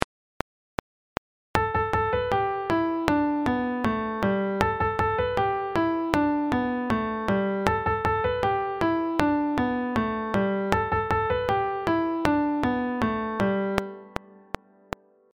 思いついた鼻歌を８拍（８カウント）作りましょう♪
ヒザや机を叩きながら歌いましょう（１・２・３・４〜と）
それを４週繰り返しましょう♪